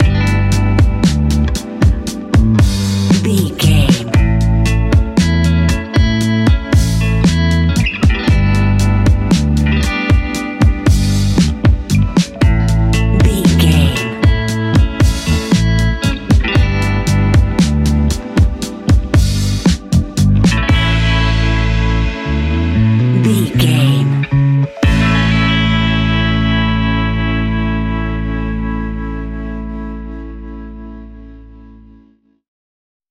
Ionian/Major
A♭
laid back
Lounge
sparse
new age
chilled electronica
ambient
atmospheric